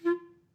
DCClar_stac_F3_v2_rr2_sum.wav